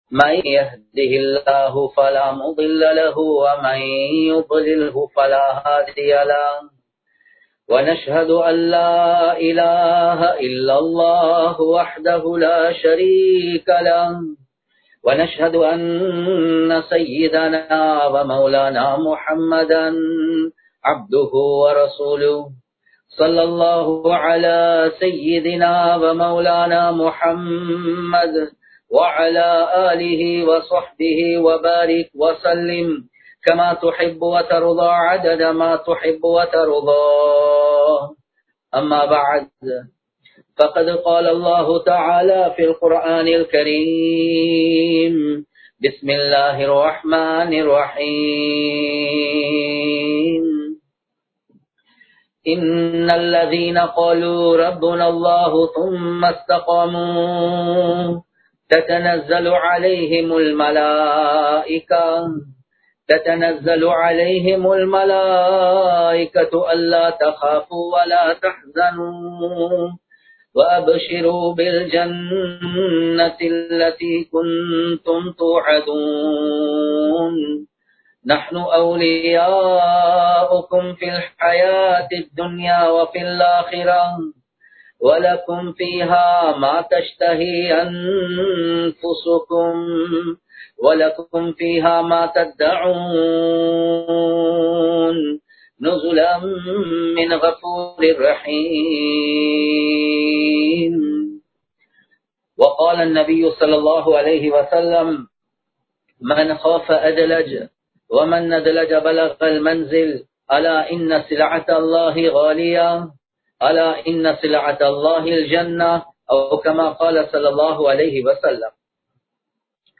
நபி (ஸல்) அவர்களின் அற்புதங்கள் | Audio Bayans | All Ceylon Muslim Youth Community | Addalaichenai
Live Stream